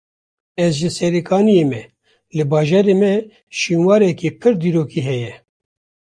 Pronunciado como (IPA)
/diːɾoːˈkiː/